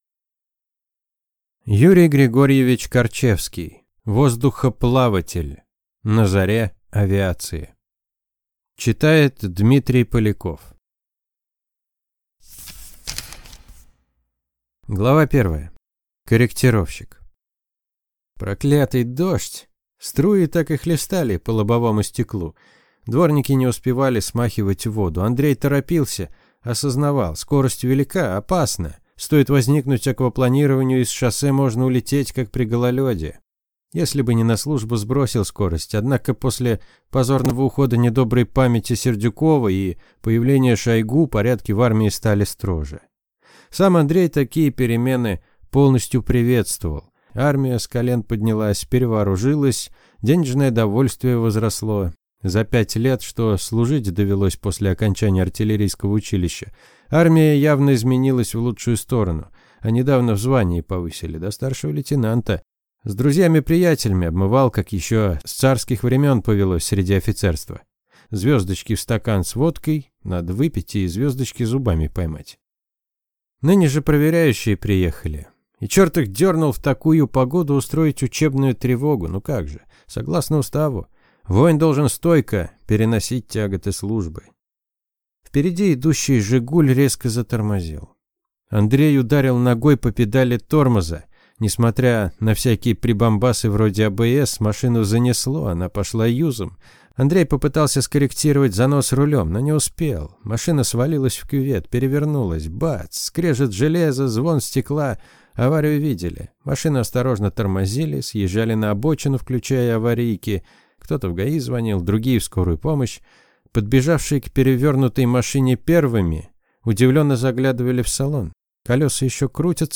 Аудиокнига Воздухоплаватель. На заре авиации | Библиотека аудиокниг